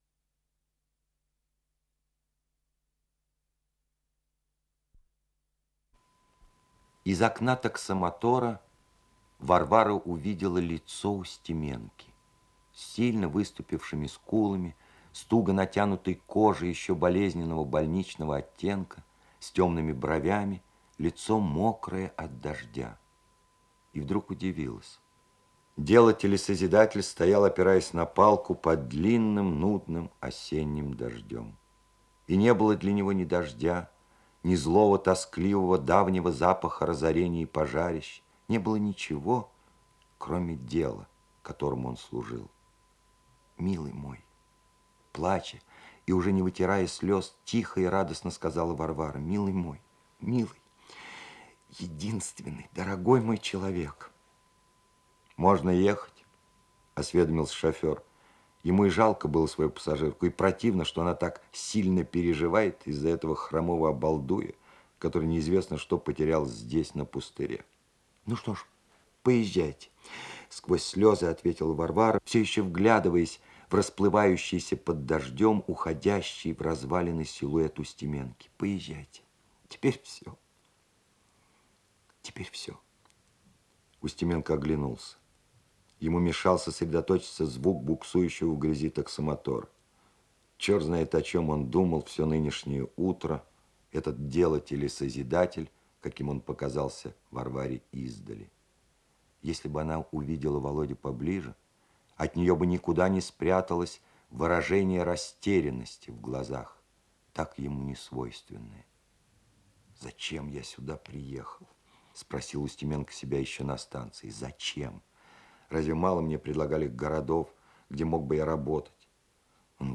Исполнитель: Алексей Баталов - чтение